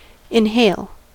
inhale: Wikimedia Commons US English Pronunciations
En-us-inhale.WAV